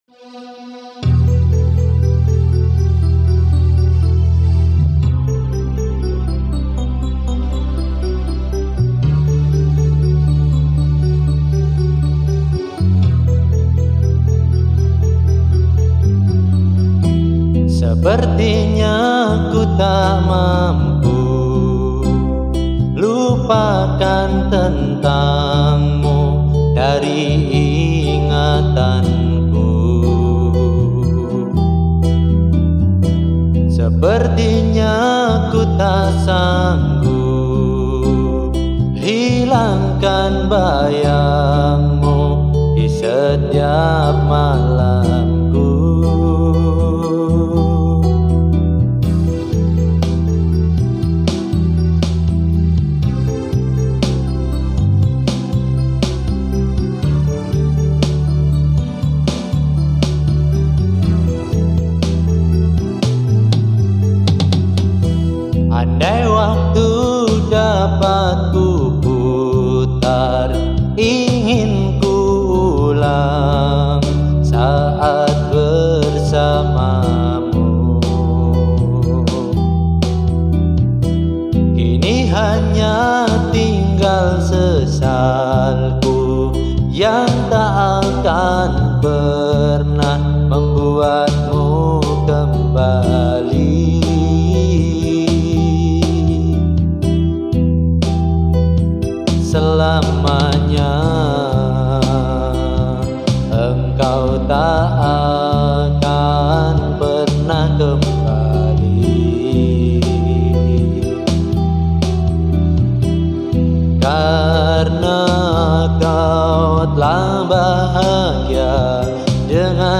Pop rock terbaru.mp3